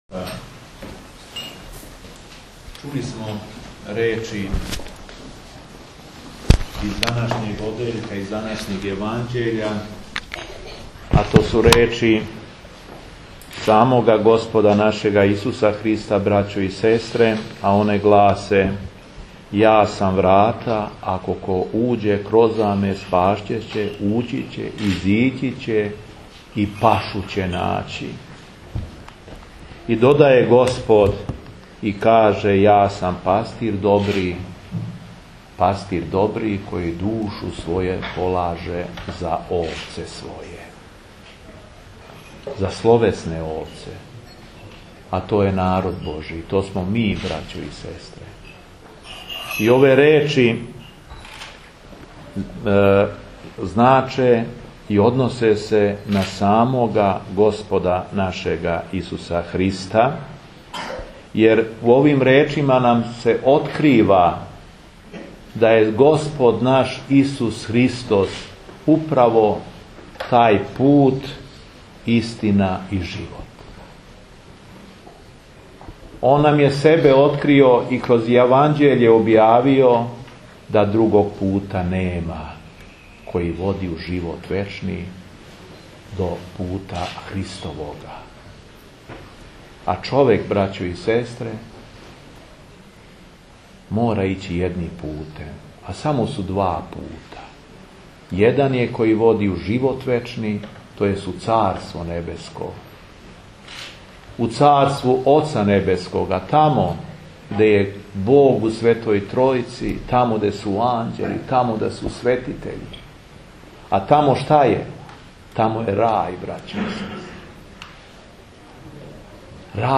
СВЕТА АРХИЈЕРЕЈСКА ЛИТУРГИЈА У ГОРЊИМ ЈАРУШИЦАМА - Епархија Шумадијска
Беседа епископа шумадијског Г. Јована